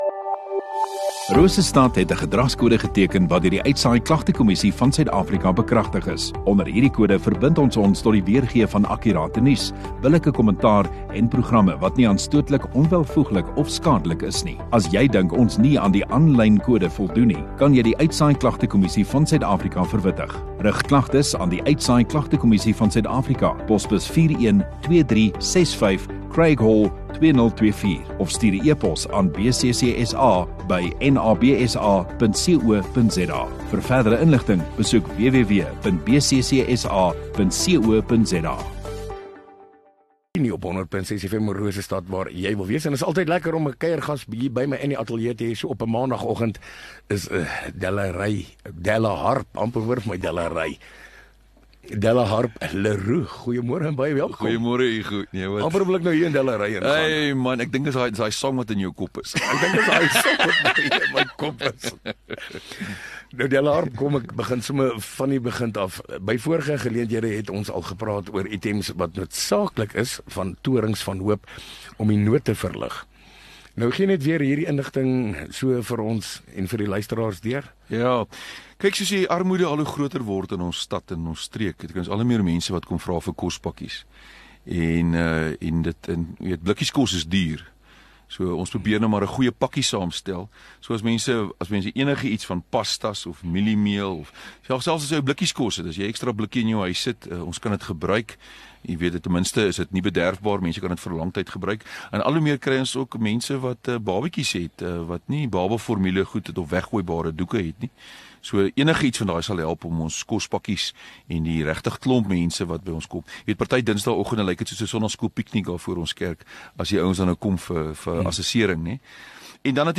Radio Rosestad View Promo Continue Radio Rosestad Install Gemeenskap Onderhoude 11 Nov Mengelmoes - Towers of Hope 7 MIN Download (3.3 MB) AF SOUTH AFRICA 00:00 Playback speed Skip backwards 15 seconds